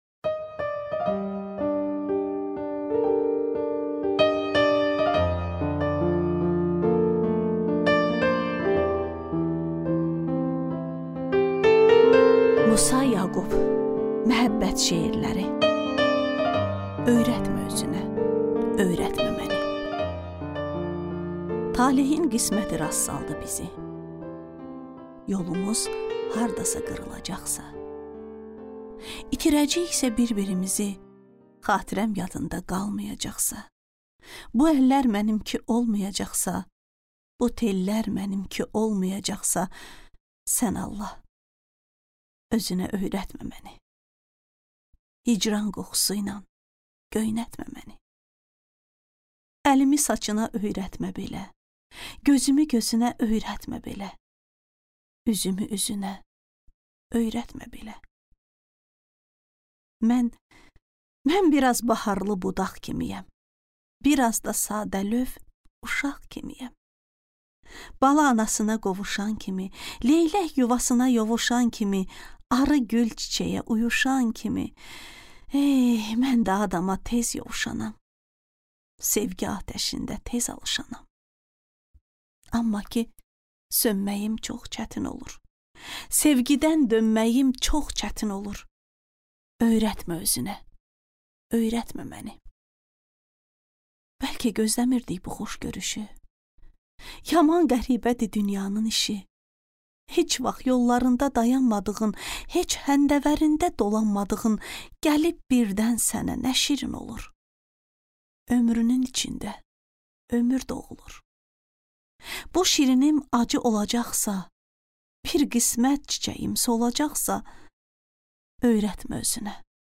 Аудиокнига Öyrətmə özünə, öyrətmə məni | Библиотека аудиокниг